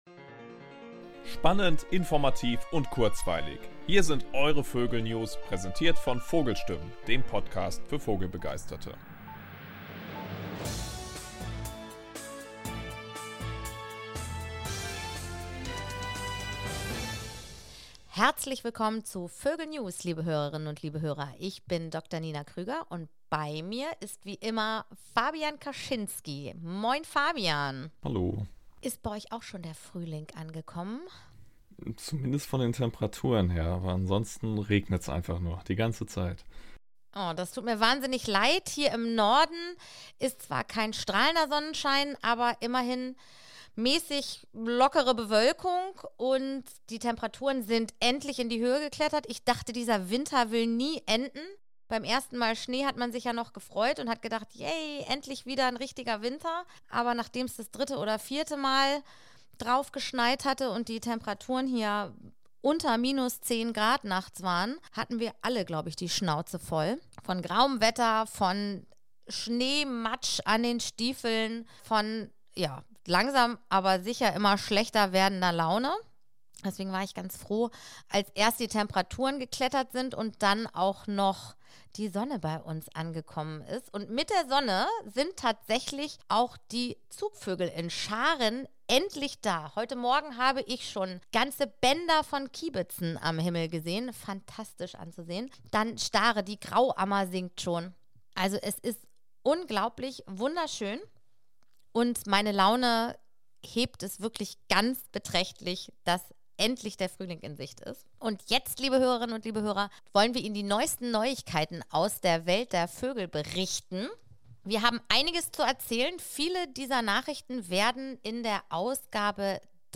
Neben interessanten Gesprächen mit Autorinnen und Autoren des Magazins, gibt es zwischen den heftbegleitenden Episoden unsere VÖGEL NEWS.